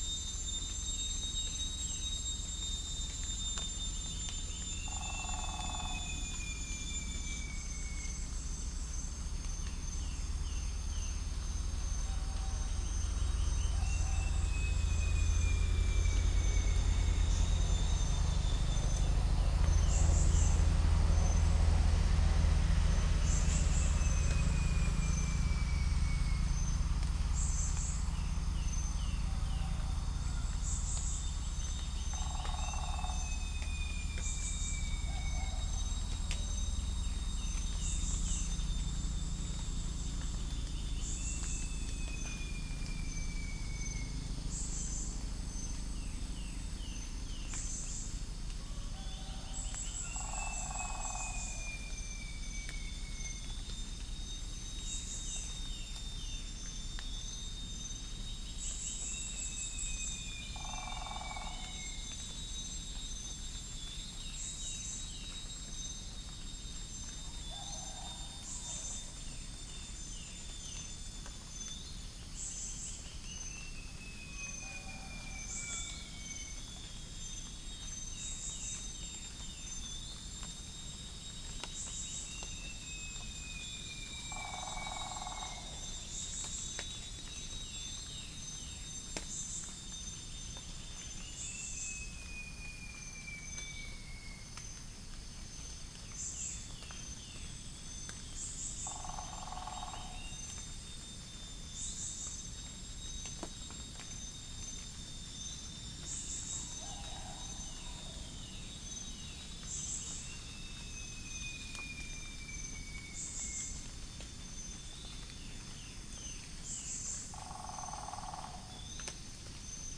Upland plots dry season 2013
Centropus sinensis
Gallus gallus domesticus
Malacopteron magnirostre
Orthotomus sericeus
Trichastoma malaccense